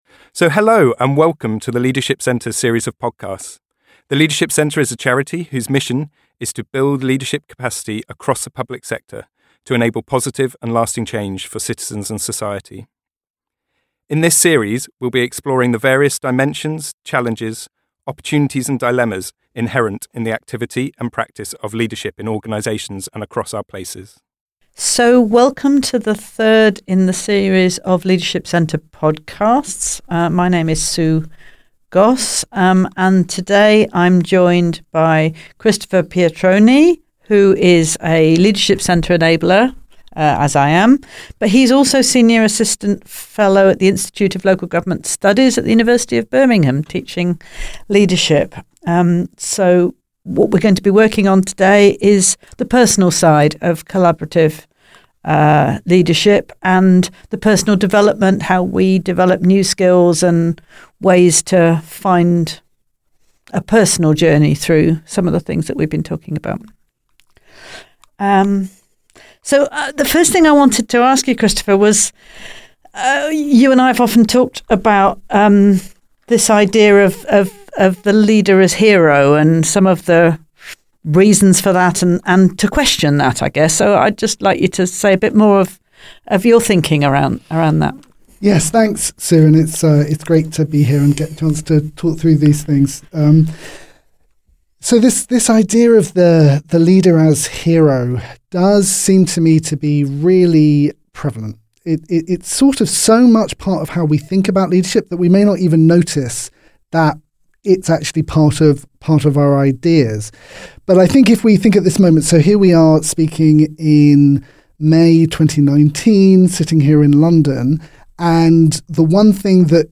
Informal conversations between some of the worlds leading experts in systems leadership. In the podcasts we will explore a range of areas of leadership development including: What is Leadership community engagement inclusive leadership immunity to change resilience adaptive leadership Public Narrative Coaching and Mentoring